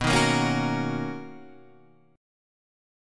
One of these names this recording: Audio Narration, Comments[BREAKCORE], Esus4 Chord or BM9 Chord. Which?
BM9 Chord